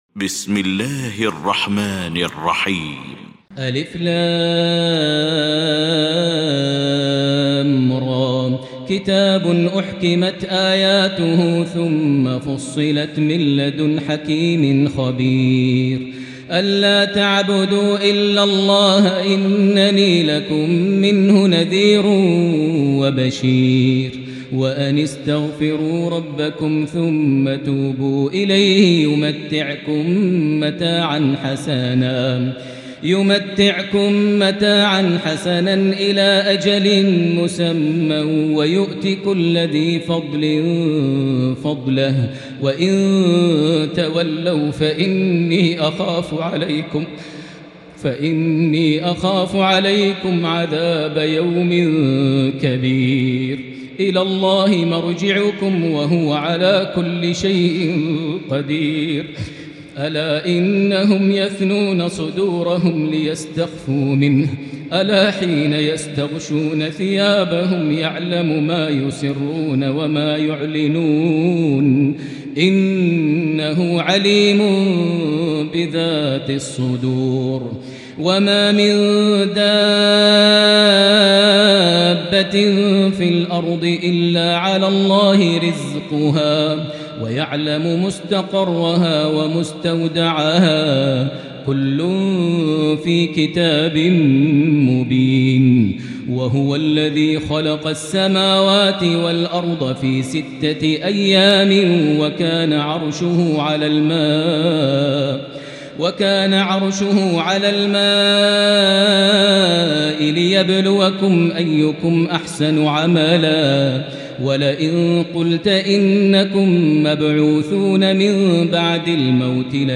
المكان: المسجد الحرام الشيخ: معالي الشيخ أ.د. بندر بليلة معالي الشيخ أ.د. بندر بليلة فضيلة الشيخ ماهر المعيقلي هود